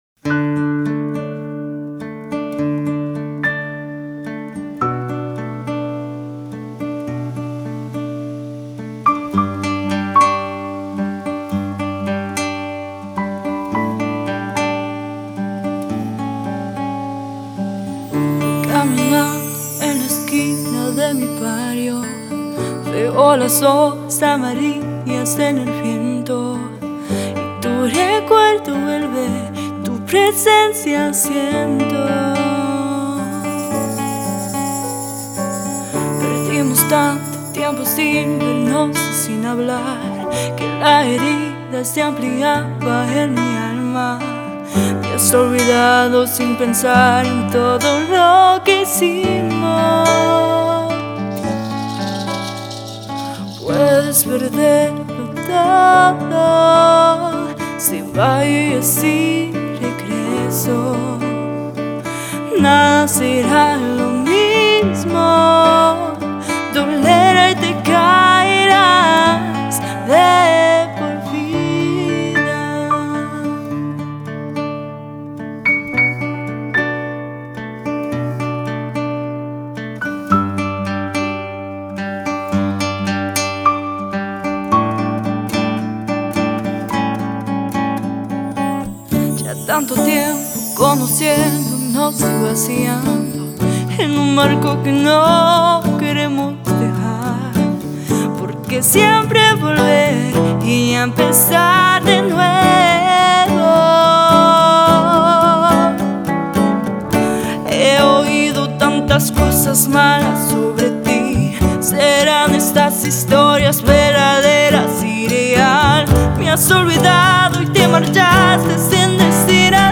CHANSONS
vocal
guitar
percussions
keyboards